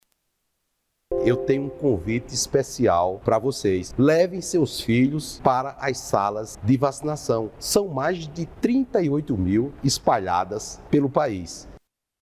Por este motivo o Ministério da Saúde prorrogou até o dia 30 de setembro. O ministro da Saúde, Marcelo Queiroga, convoca pais e responsáveis de crianças na faixa etária de 1 a menores de 5 anos de idade.
Sonora-Marcelo-Queiroga-ministro-da-saude.mp3